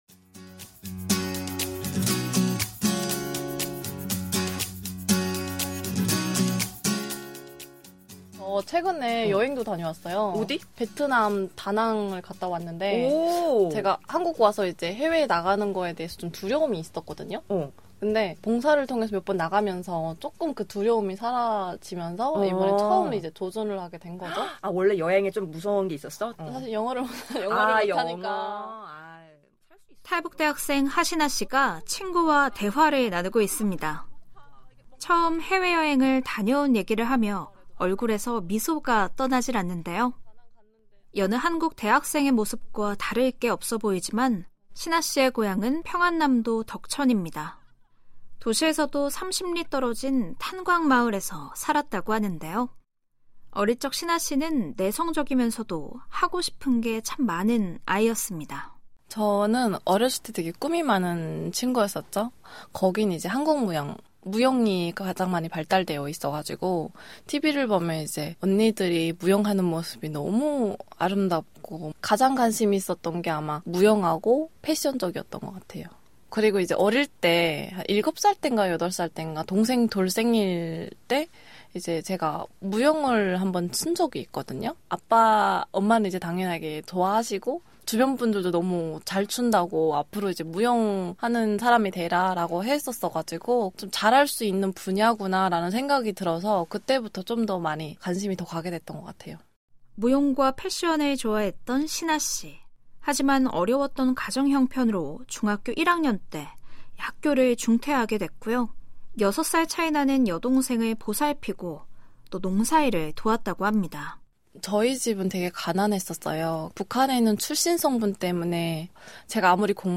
VOA 한국어 TV 프로그램 VOA 한국어 라디오 프로그램